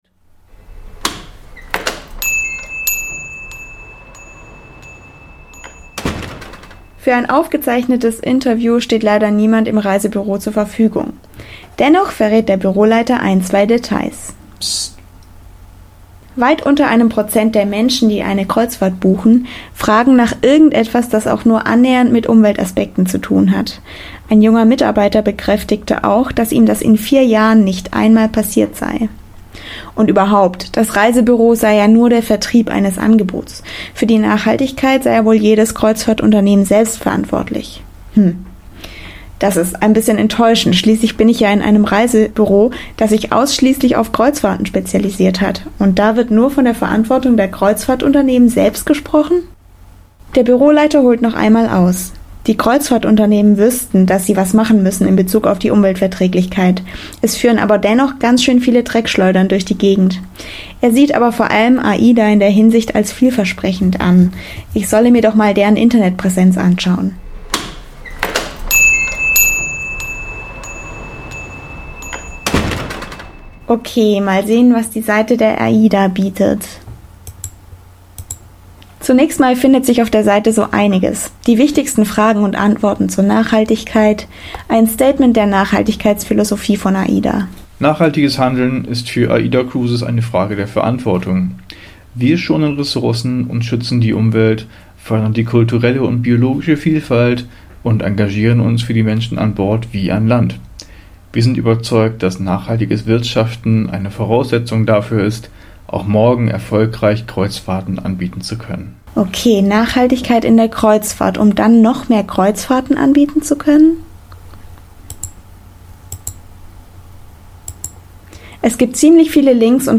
Sie hat sich in einem Reisebüro umgehört: